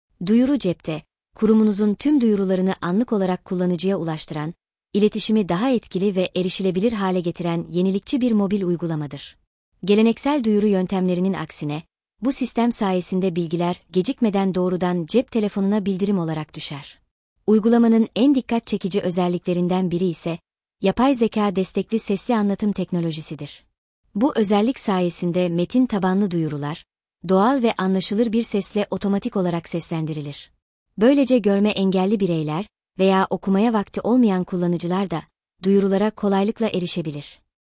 Bu özellik sayesinde metin tabanlı duyurular, doğal ve anlaşılır bir sesle otomatik olarak seslendirilir.
ai_seslendirme_1.mp3